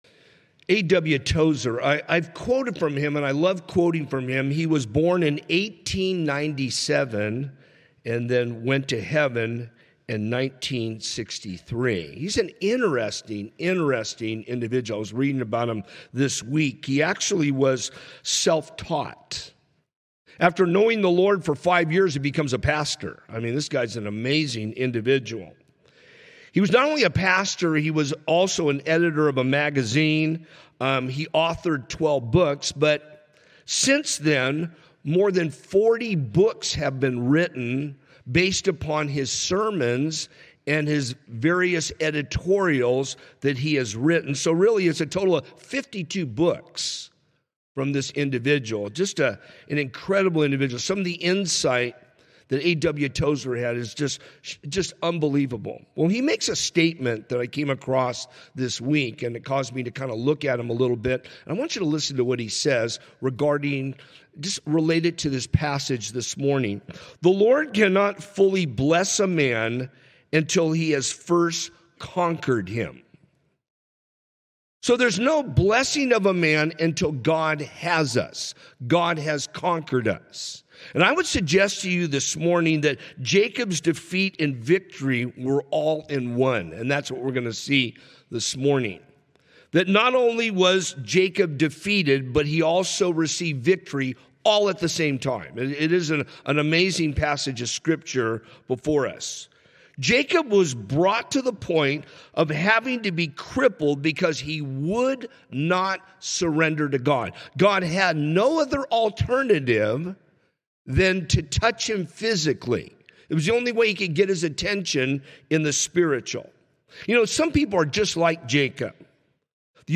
From Series: "Sunday Morning - 10:30"